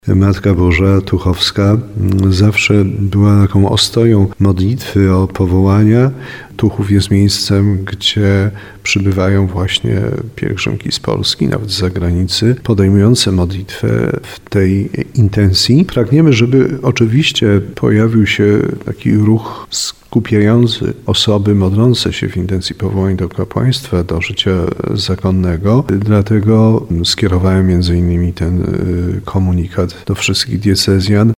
– powiedział bp Andrzej Jeż.